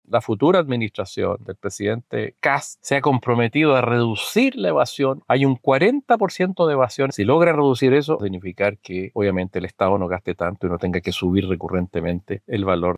Al respecto, desde la Comisión de Transportes de la Cámara Baja, el diputado Jaime Mulet (FRVS) señaló que esto será un desafío para el mandato del presidente electo José Antonio Kast, sobre todo, en lo que respecta a la evasión del pago en los servicios.
cu-alza-transporte-mulet.mp3